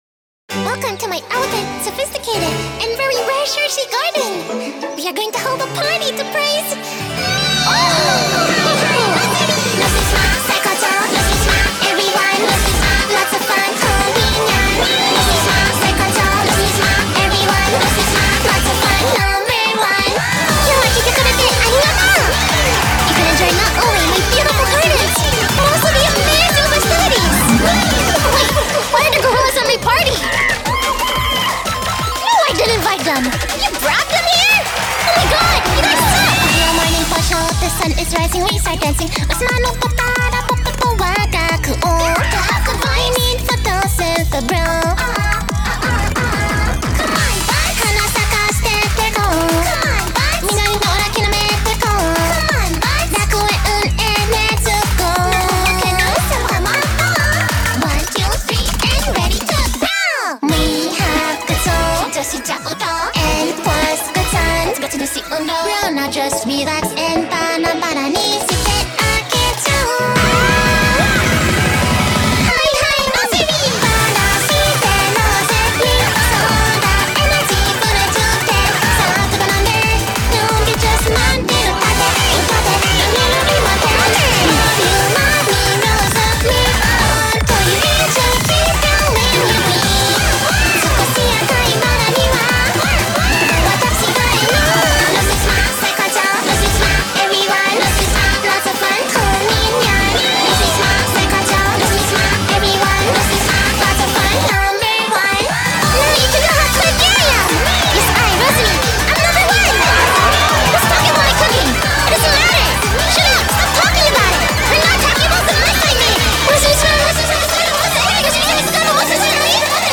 BPM180
MP3 QualityMusic Cut